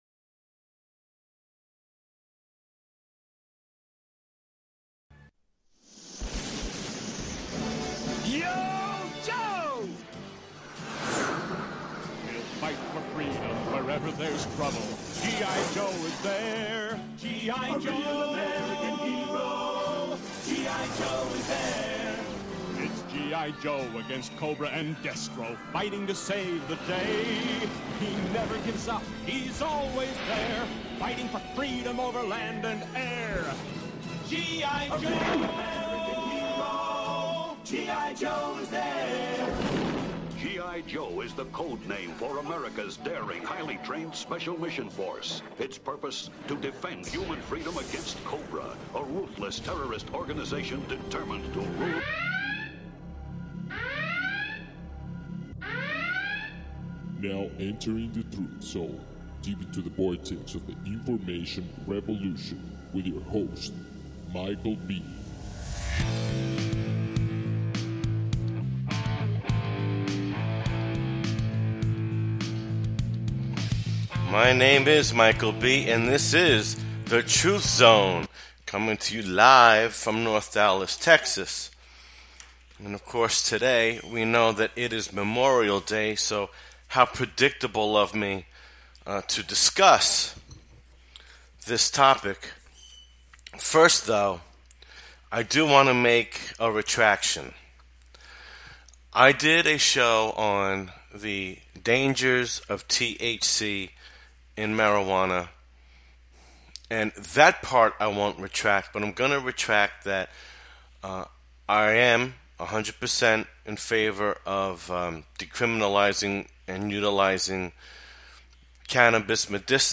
The Truth Zone is in your face radio and not for the weak of heart.